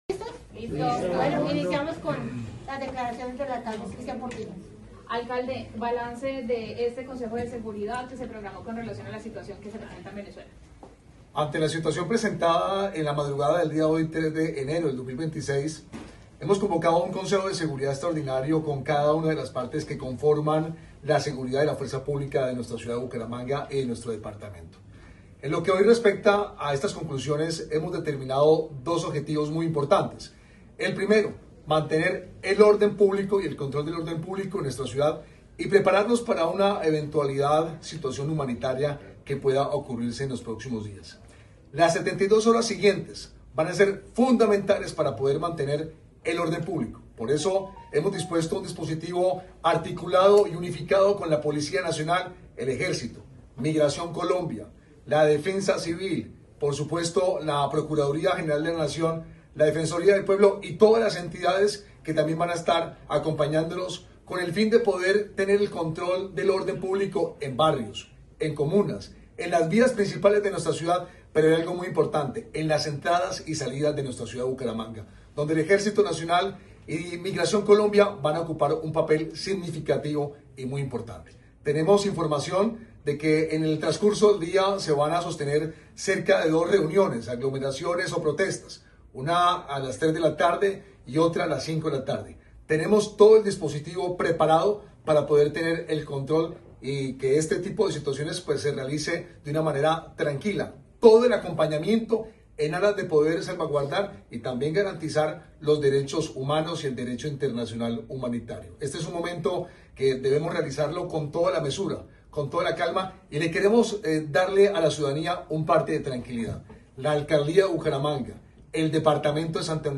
Cristian Portilla, alcalde de Bucaramanga